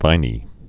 (vīnē)